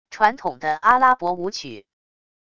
传统的阿拉伯舞曲wav音频